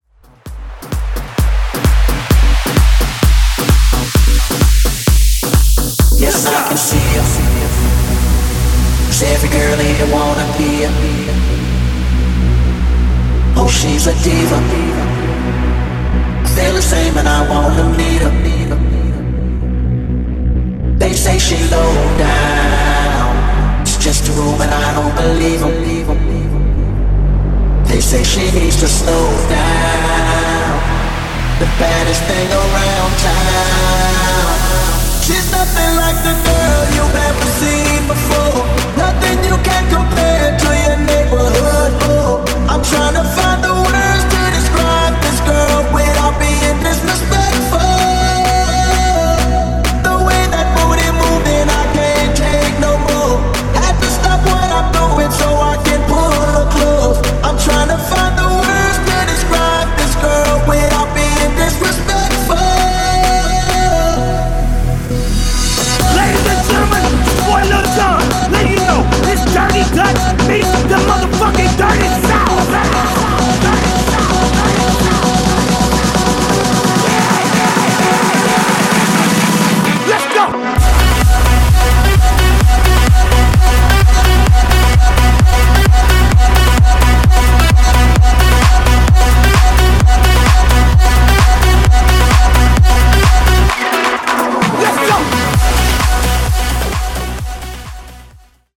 Club Extended)Date Added